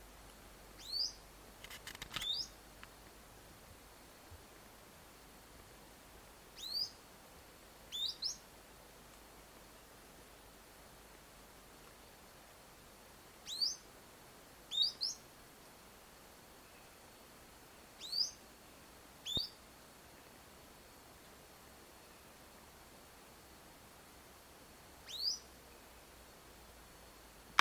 Ochre-cheeked Spinetail (Synallaxis scutata)
Un ejemplar vocalizando a la salida del sendero mapuche (500 mts)
Condition: Wild
Certainty: Observed, Recorded vocal